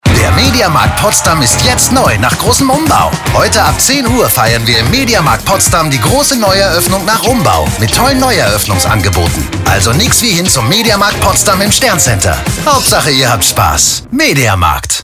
Werbesprecher